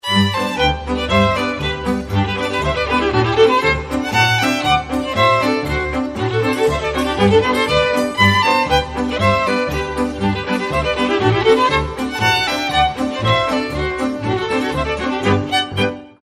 Volkstänze aus Niederösterreich